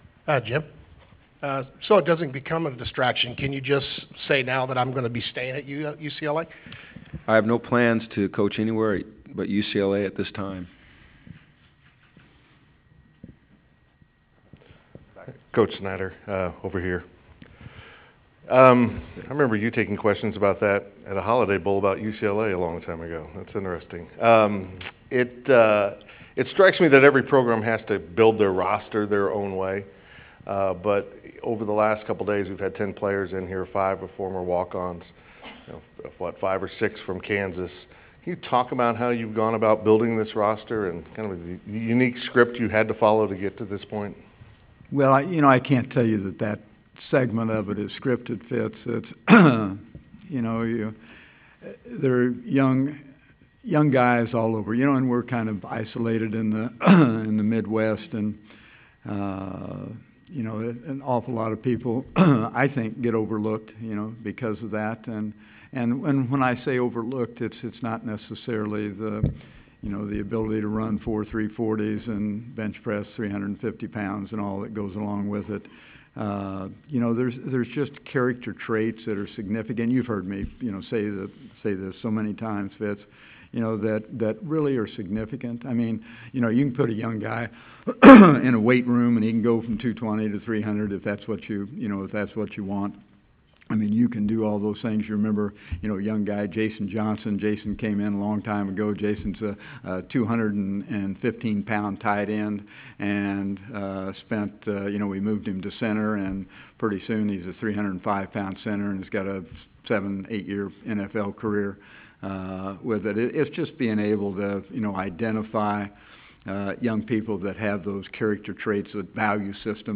Head Coaches Press Conference Pt. 2
Kansas State head coach Bill Snyder and UCLA head coach Jim Mora.
ABowl-2014-Coaches-PC-Pt2.wav